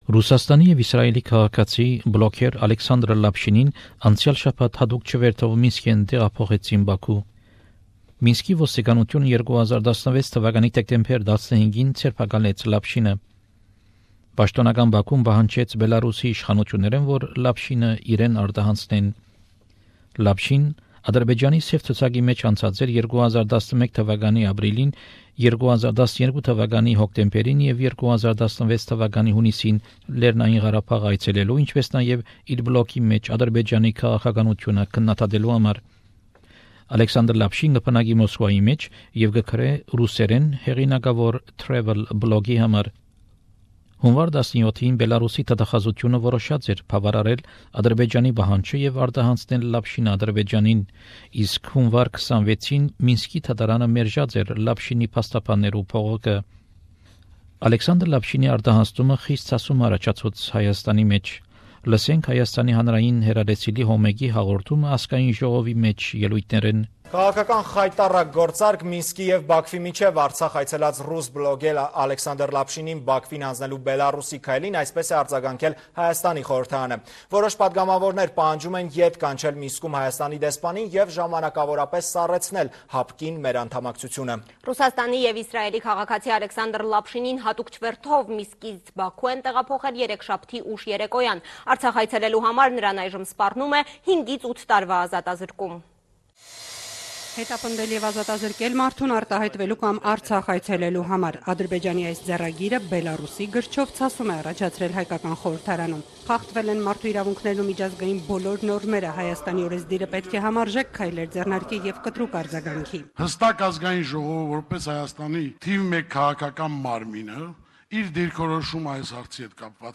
This is a summary of the events and includes reports from H1 Public TV Company of Armenia.